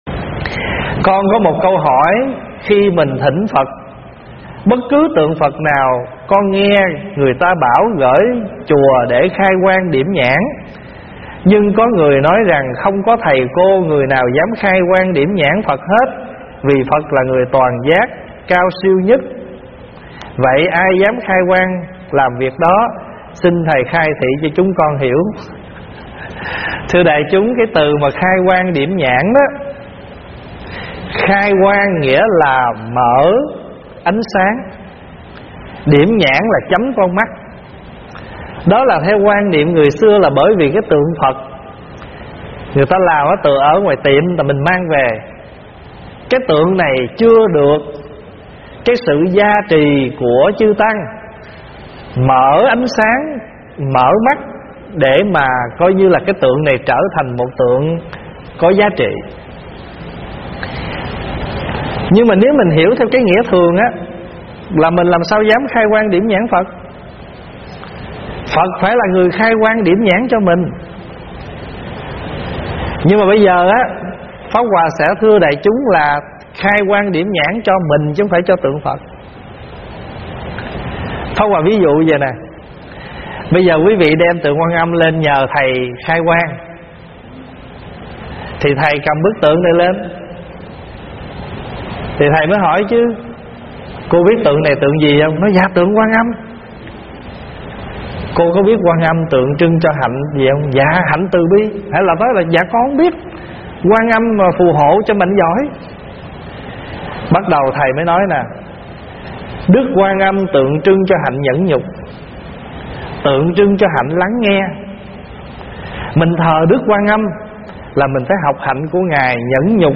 Nghe Mp3 thuyết pháp Ý Nghĩa của "Khai Quang Điểm Nhãn"
Mời quý phật tử nghe mp3 vấn đáp Ý Nghĩa của "Khai Quang Điểm Nhãn"